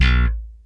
B1 3 F.BASS.wav